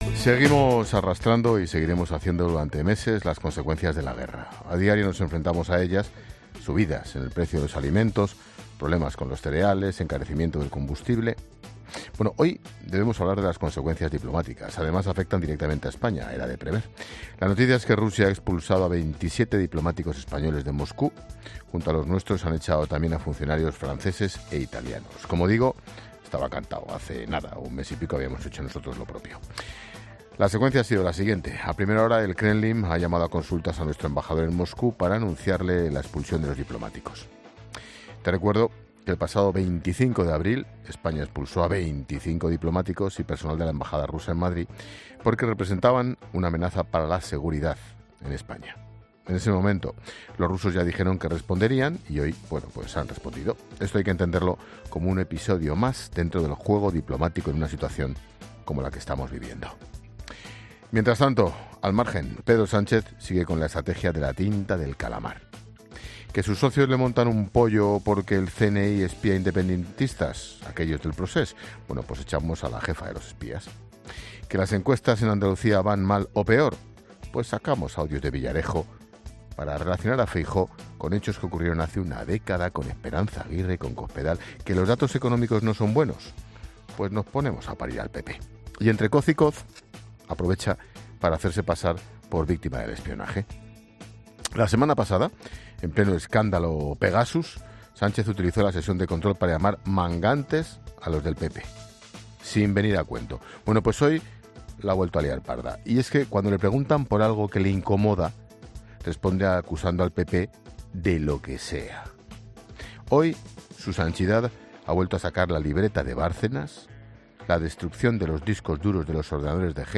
Monólogo de Expósito
El director de 'La Linterna' reflexiona sobre la situación diplomática española, económica y del Hemiciclo